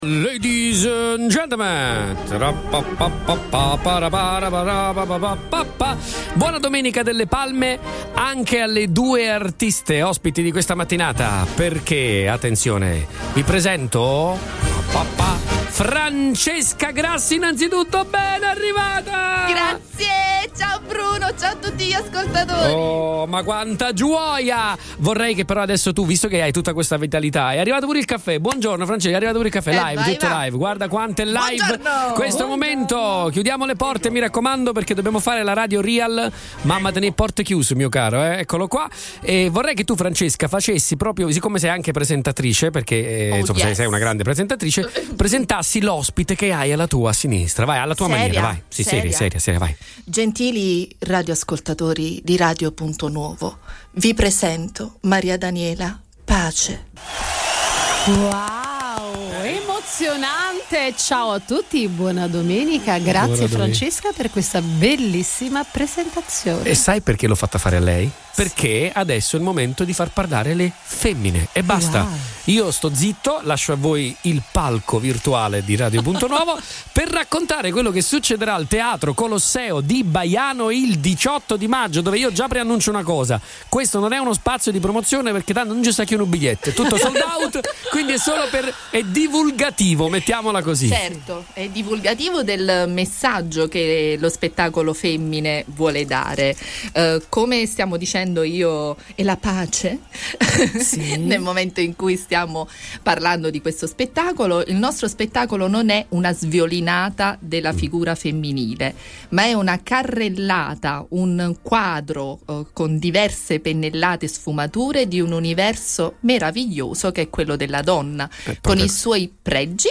Le due artiste sono state ospiti questa mattina negli studi di Radio Punto Nuovo per svelare al pubblico i dettagli di questa inedita produzione che andrà in scena sabato 18 maggio presso il Teatro Colosseo di Baiano.